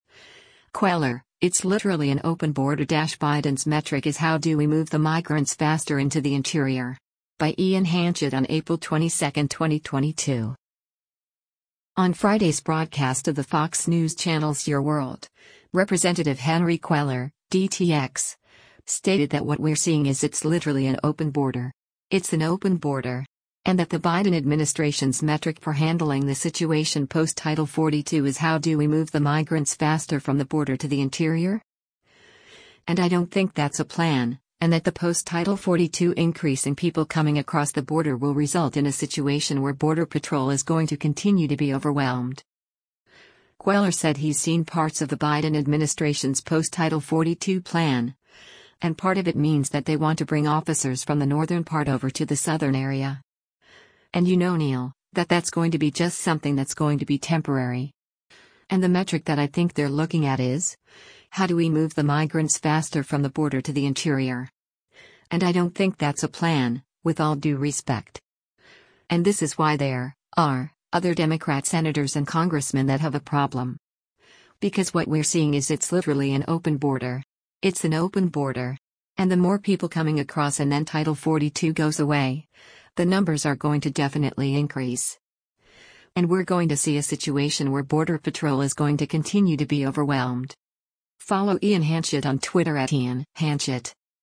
On Friday’s broadcast of the Fox News Channel’s “Your World,” Rep. Henry Cuellar (D-TX) stated that “what we’re seeing is it’s literally an open border. It’s an open border.”